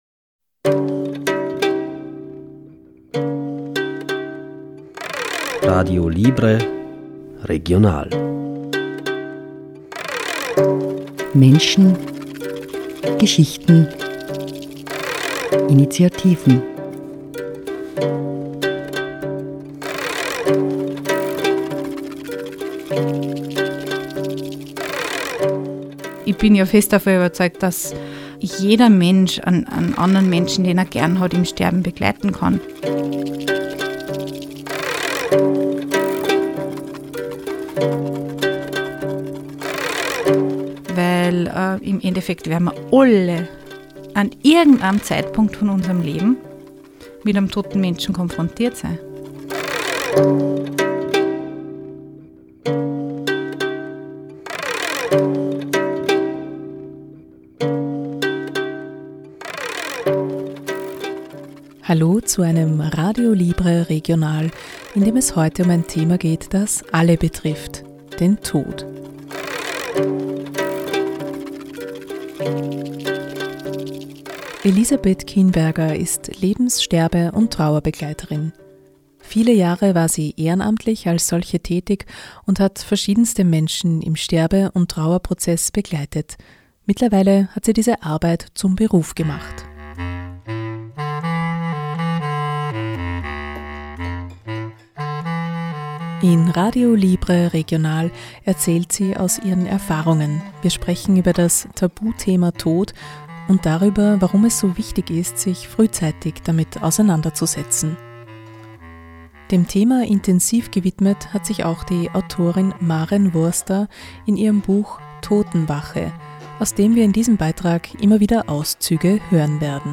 In Radio Libre Regional erzählt sie aus ihren Erfahrungen, wir sprechen über das „Tabuthema Tod“ und darüber, warum es so wichtig ist, sich frühzeitig damit auseinanderzusetzen. Außerdem gibt es Antwort auf die Frage: Haben Sterbebegleiter:innen eigentlich Angst vorm Tod?